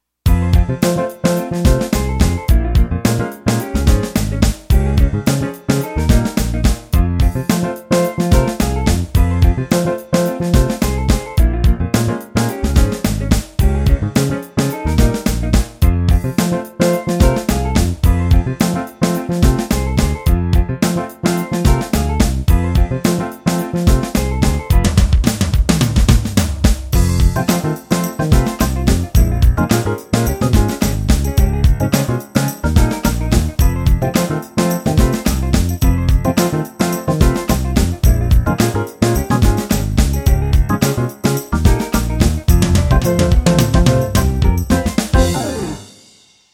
Nice, slightly funky late 60s-00s soul music style.
Works for: ROCK, POP, R&B & CCM 13